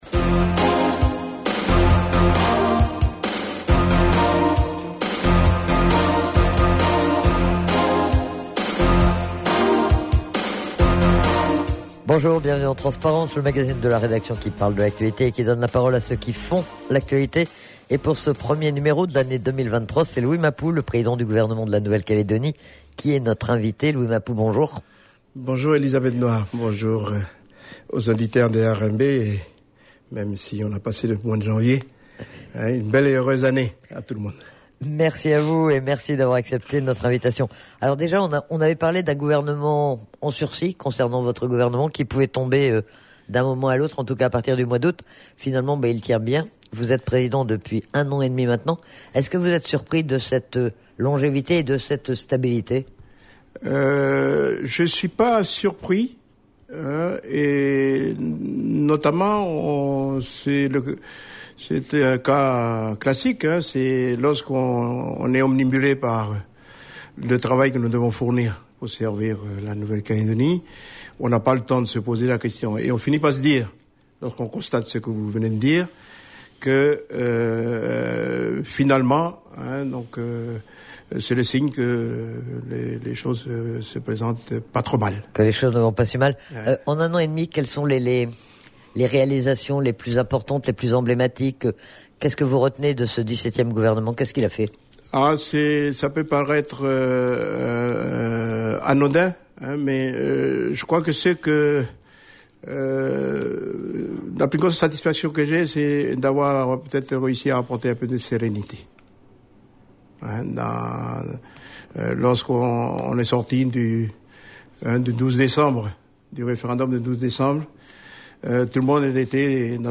Pour ce premier numéro de l'année 2023, c'est Louis Mapou, le président du gouvernement de la Nouvelle-Calédonie, qui est notre invité. Il est interrogé sur l'actualité du gouvernement, la situation financière de la Nouvelle-Calédonie ou encore l'actualité politique.